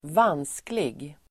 Uttal: [²v'an:sklig]